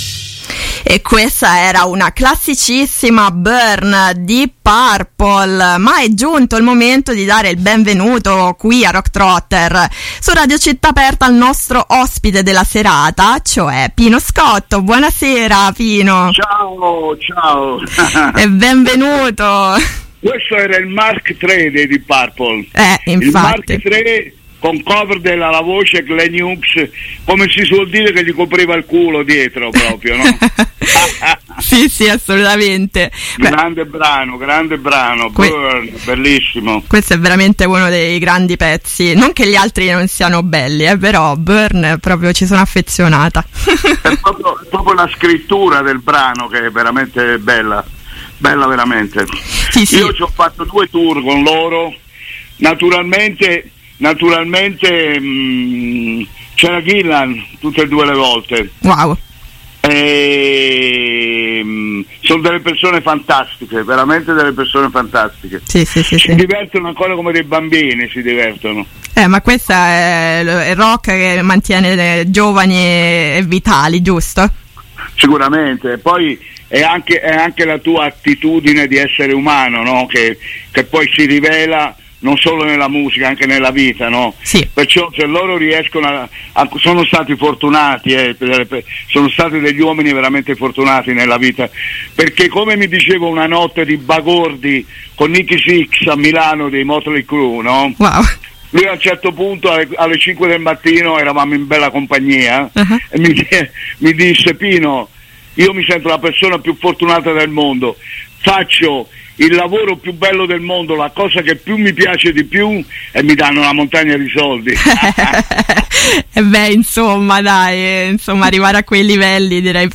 INTERVISTA A PINO SCOTTO
intervista-a-pino-scotto-rocktrotter.mp3